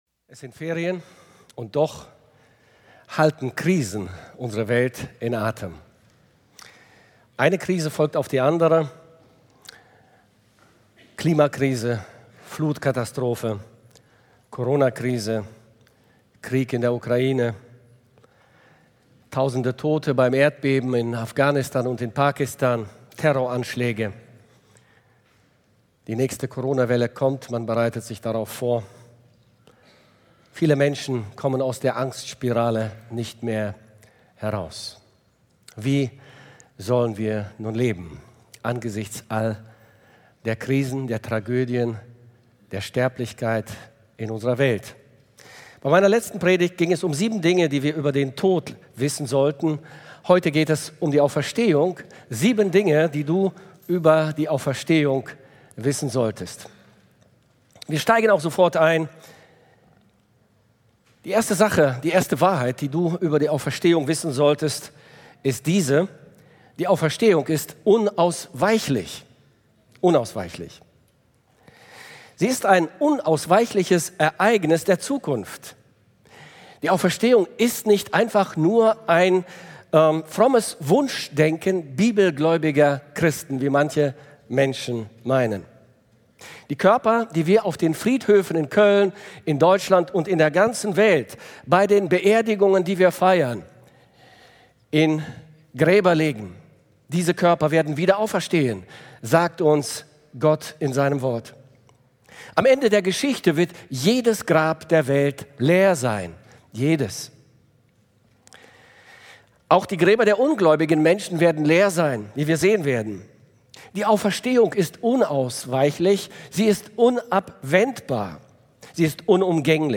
Spotify laden Download-Bereich mp3-Audio Predigt Reflexion